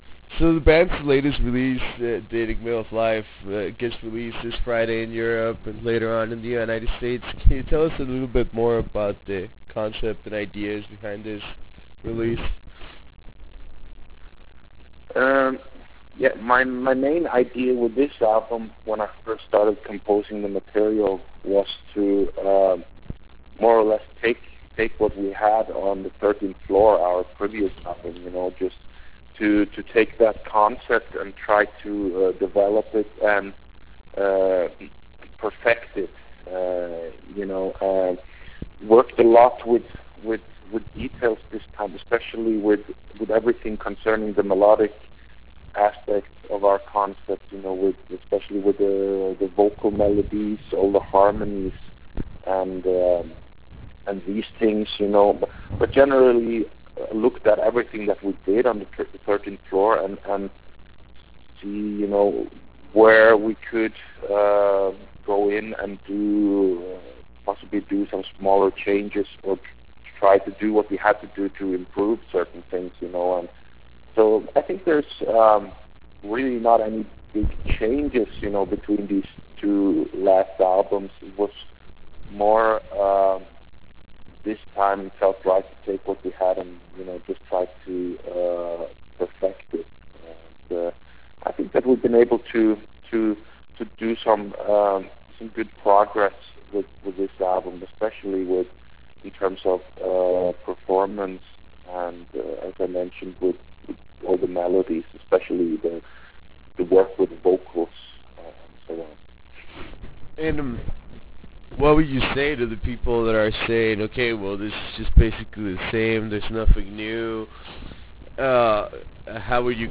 Interview with Sirenia - Morten Veland
Interview with Morten Veland - Sirenia.wav